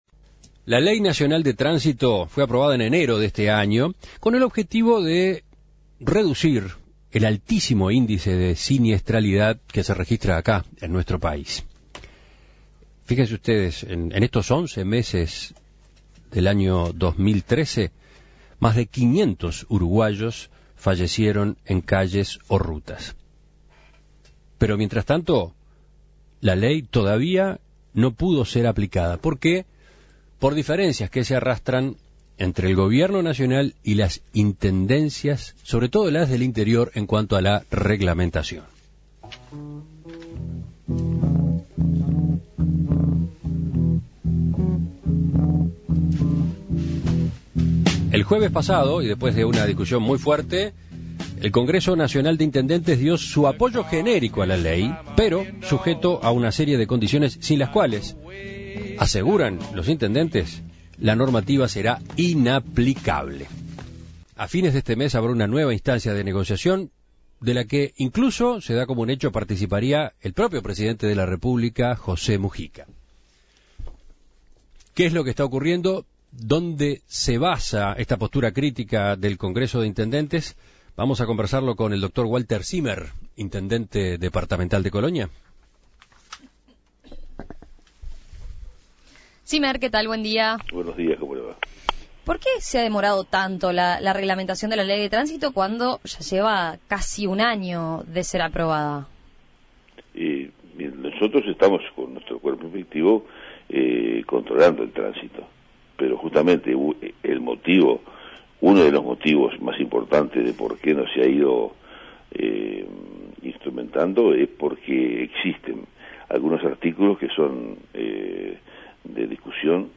La Ley Nacional de Tránsito no se ha podido aplicar por falta de acuerdo entre el Gobierno nacional y las Intendencias. El Congreso Nacional de Intendentes (CNI) apoya la ley pero con condiciones que deben ser incluidas en la norma, o de lo contrario será "inaplicable". Para conocer la postura del CNI, En Perspectiva dialogó con el intendente de Colonia, Walter Zimmer, quien explicó que hay algunos aspectos de esta ley que no son aplicables a las realidades de cada departamento.